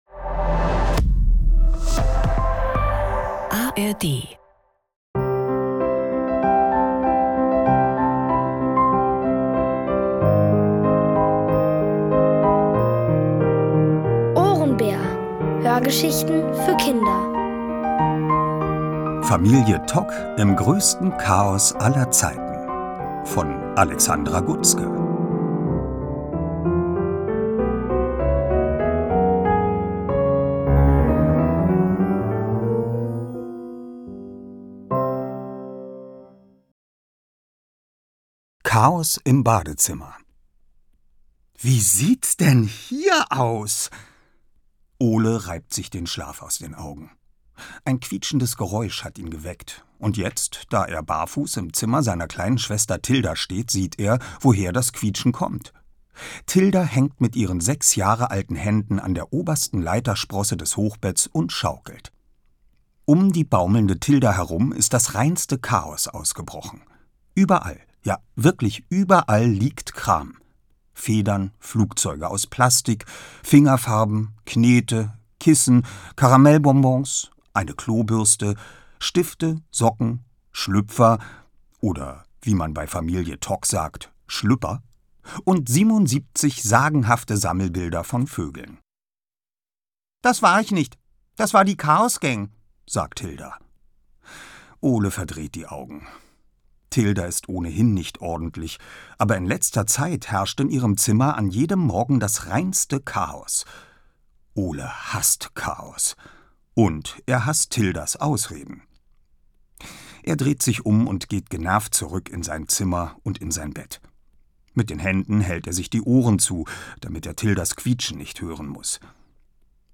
Familie Tok im größten Chaos aller Zeiten | Die komplette Hörgeschichte!
Es liest: Oliver Rohrbeck.